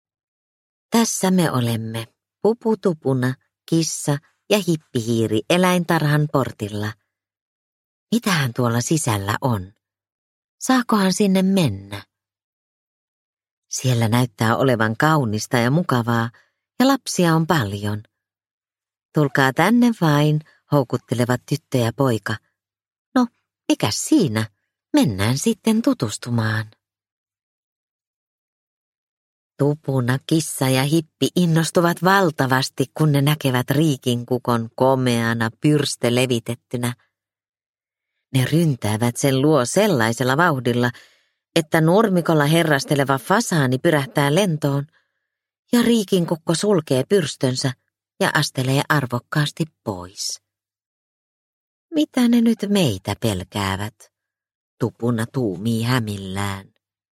Pupu Tupuna eläintarhassa – Ljudbok – Laddas ner